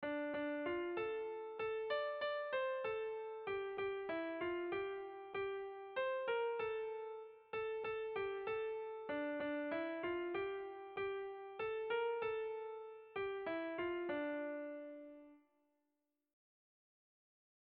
Erromantzea
Oñati < Debagoiena < Gipuzkoa < Euskal Herria
Lauko handia (hg) / Bi puntuko handia (ip)
AB